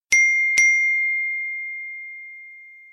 Kategorien: Sms Töne